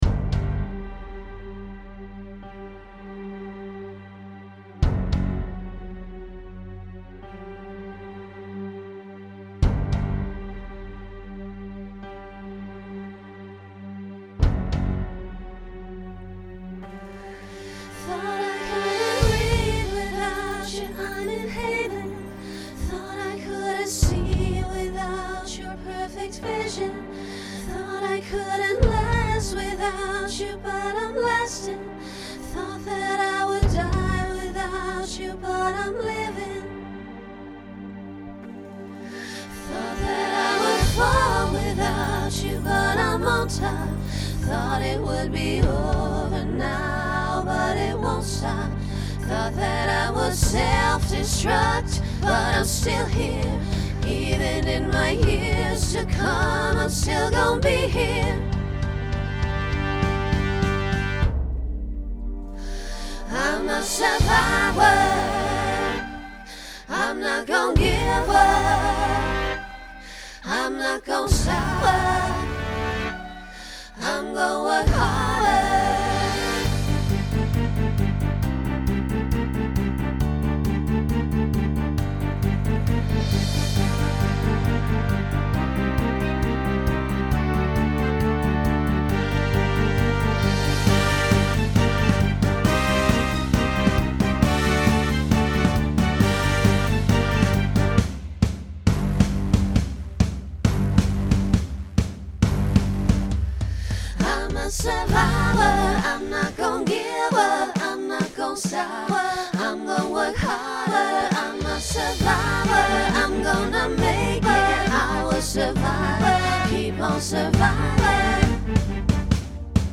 Pop/Dance , Rock
Transition Voicing SSA